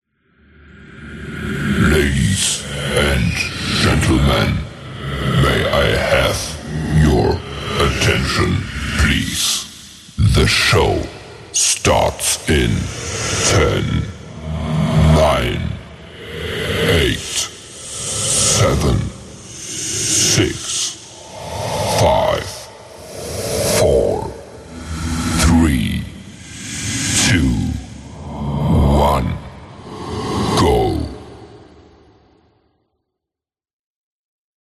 Отсчет игрового шоу вступление глубокий мужской голос обработанный отсчет от 10 до 0